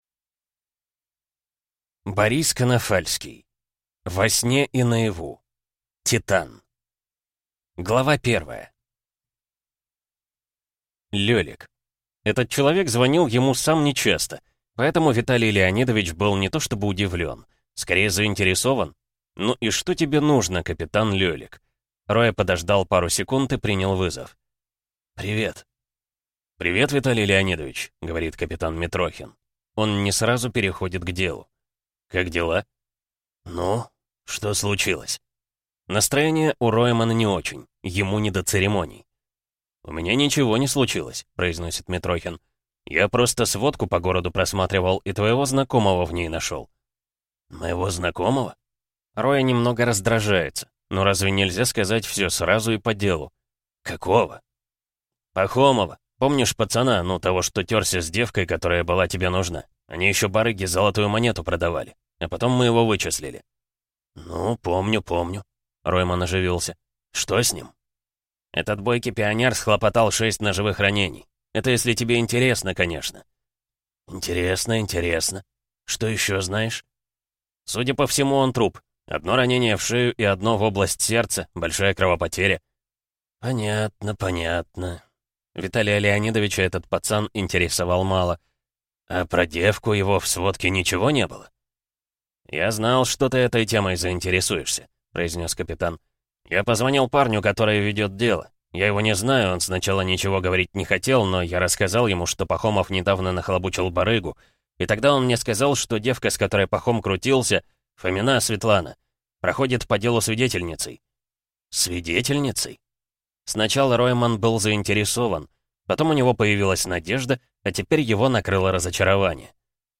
Аудиокнига Во сне и наяву. Титан | Библиотека аудиокниг